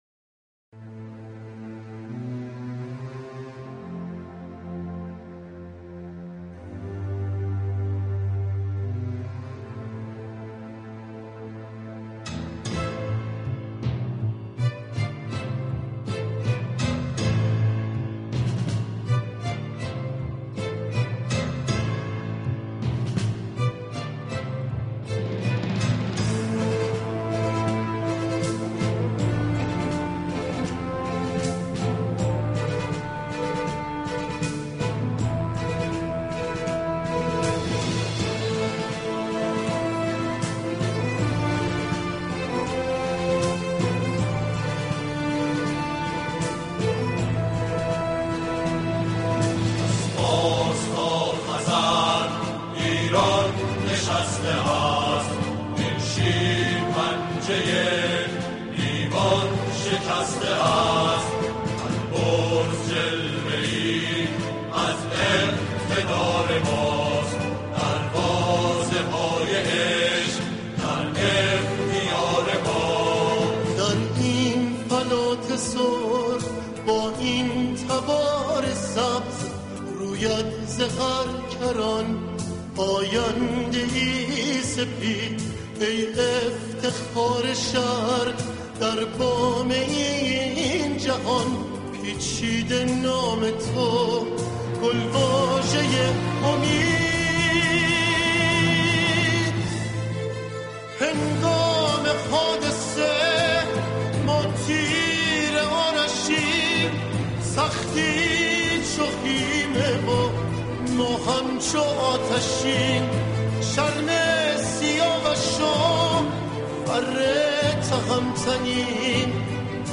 اثر ارکسترال